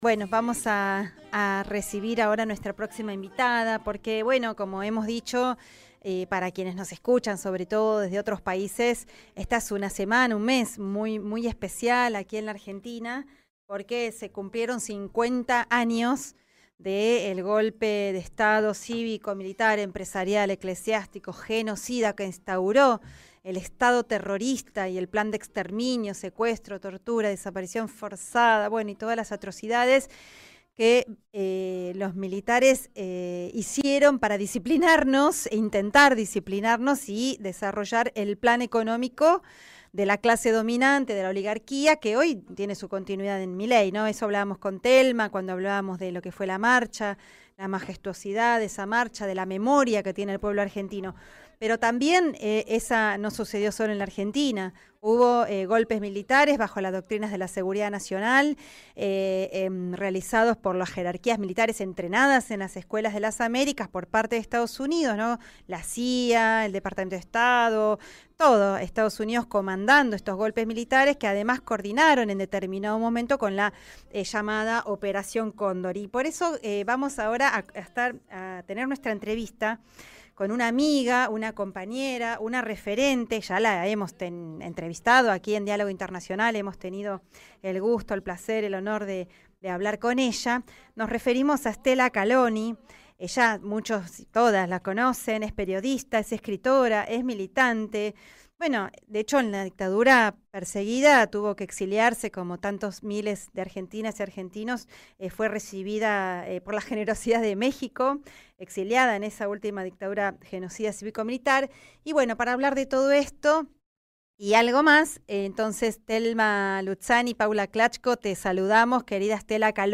Stella Calloni en Diálogo Internacional Texto de la nota: Stella Calloni pasó por Radio UNDAV En esta edición, conversamos con Stella Calloni, periodista, escritora y referente en la investigación sobre las dictaduras en América Latina. Durante la entrevista, reflexionó sobre los 50 años del golpe de Estado en Argentina, destacó la masividad de las movilizaciones por la memoria y analizó el rol histórico del movimiento de derechos humanos, sus consignas y su vigencia en el presente. Además, abordó la dimensión regional de las dictaduras, el impacto de la Operación Cóndor y los desafíos actuales en torno a la soberanía, la información y la construcción de una verdad sin ambigüedades.